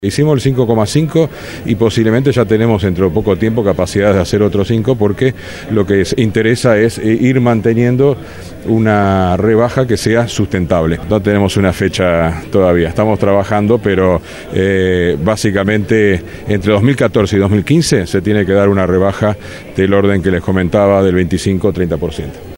El gobierno confirmó una nueva rebaja en la tarifa de UTE de 5,5% según anunció en rueda de prensa el ministro de Industria, Roberto Kreimerman, que agregó que entre este año y el que viene la tarifa acumulará una baja cercana al 30%.